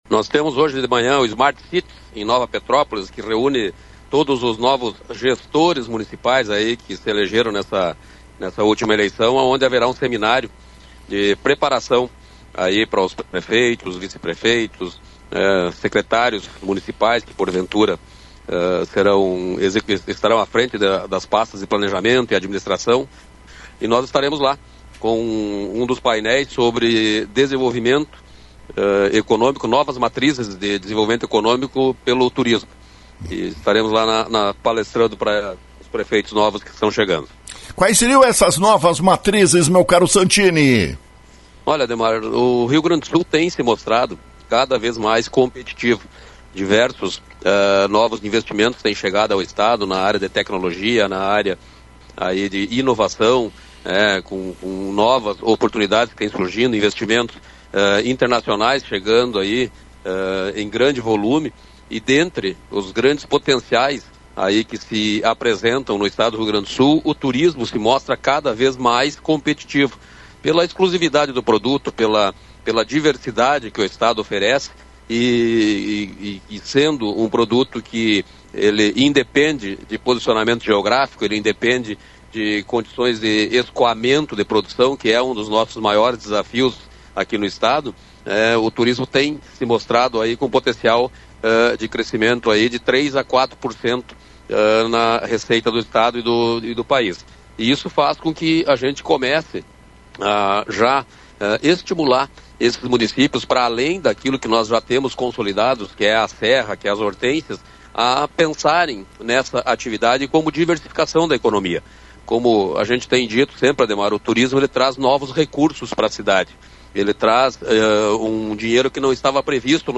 Informação foi transmitida na manhã desta terça-feira pelo secretário estadual do Turismo, Ronaldo Santini, em entrevista à Rádio Lagoa FM. Município de Lagoa Vermelha busca recursos junto ao Estado para viabilizar a construção de Rua Coberta e a revitalização da lagoa que deu origem ao nome do município.